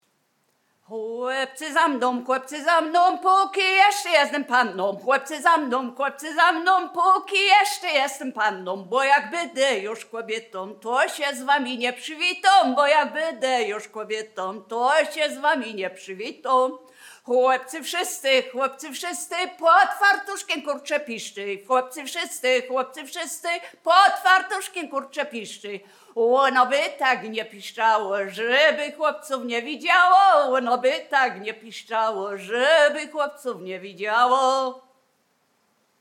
Biskupizna
Wielkopolska
Obyczajowa
Array liryczne obyczajowe miłosne